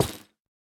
break1.ogg